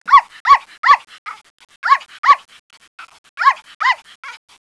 dog_pomeranian.wav